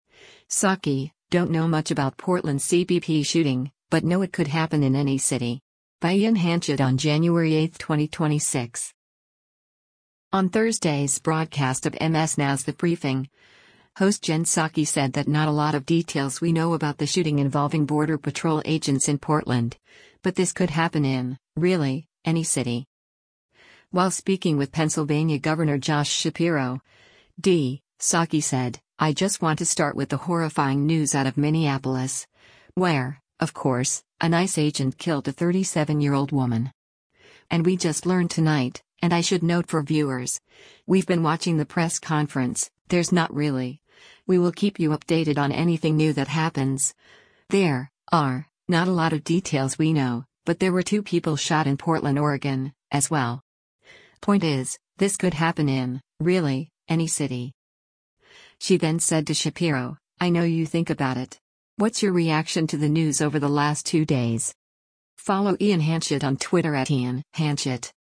On Thursday’s broadcast of MS NOW’s “The Briefing,” host Jen Psaki said that “not a lot of details we know” about the shooting involving Border Patrol agents in Portland, but “this could happen in, really, any city.”